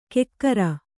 ♪ kekkara